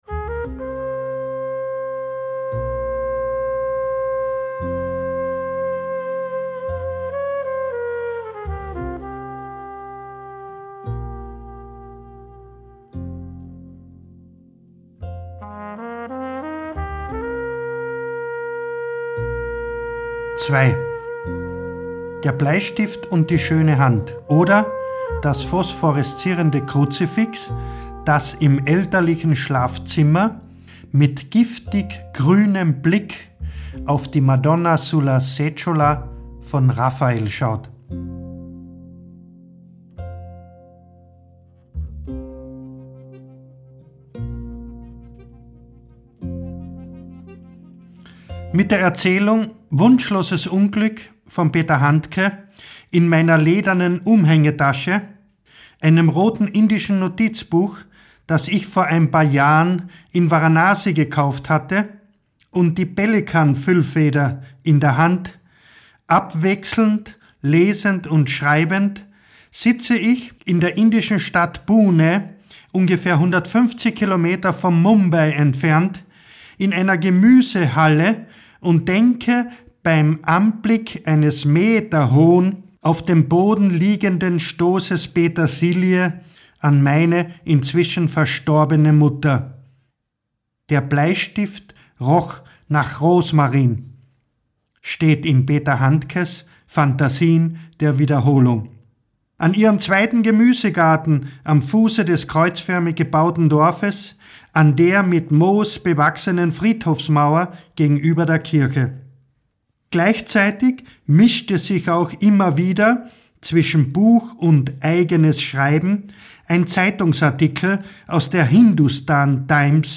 Ungekürzte Autoren - Lesung
Josef Winkler (Sprecher)